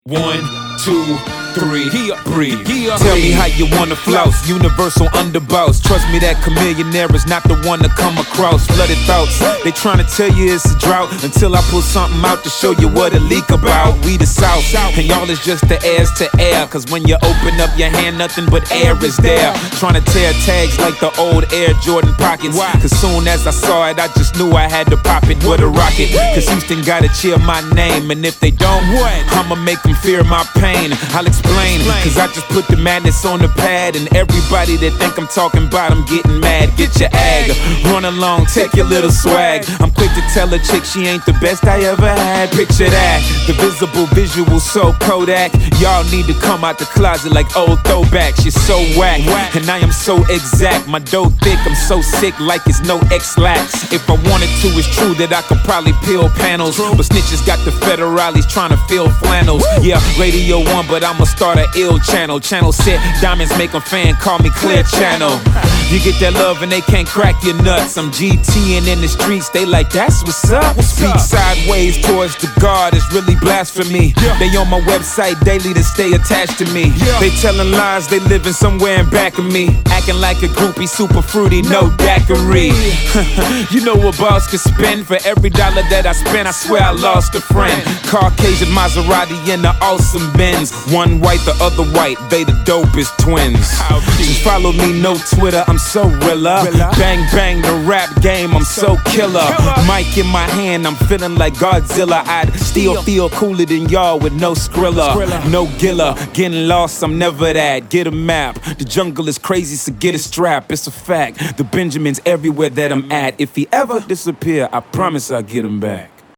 Genre: Hip-Hop.